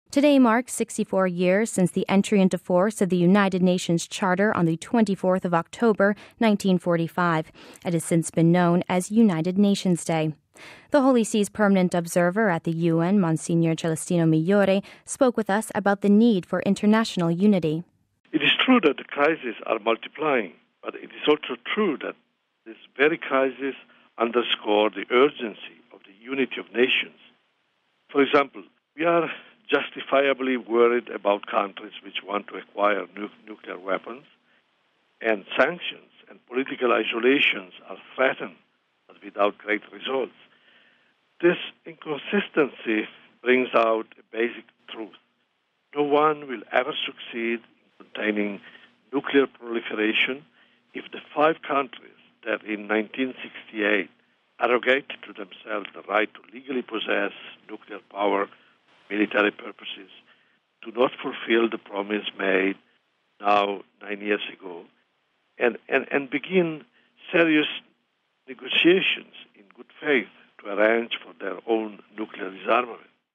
The Holy See's permanent observer at the UN, Monsignor Celestino Migliore, spoke with us about the need for international unity: